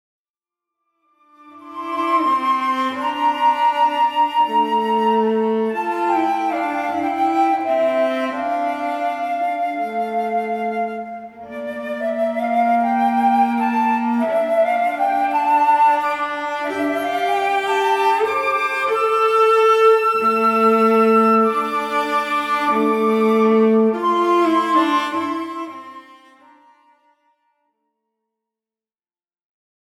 Sonate pour flûte et violoncelle